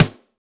BEAT SD 02.WAV